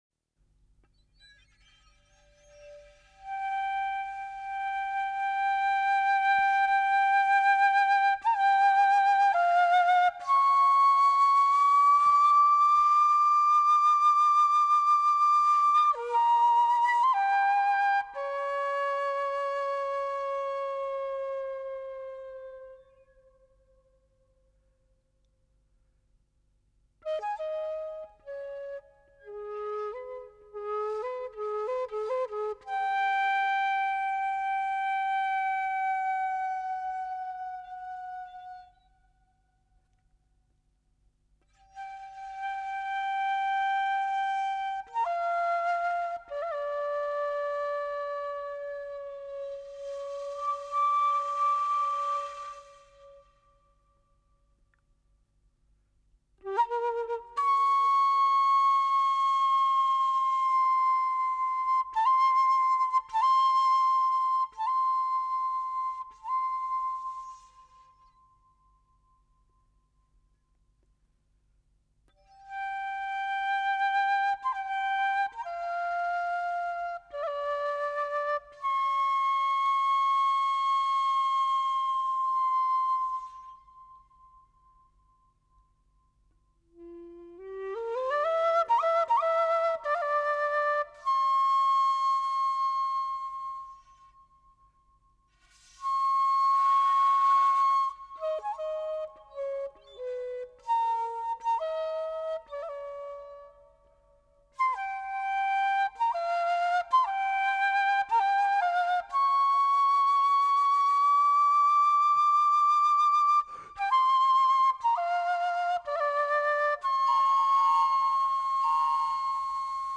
Instrumento solo / con o sin electrónica, Todas las obras
Para Quena y sonidos fijos.
Enregistrement en studio
quena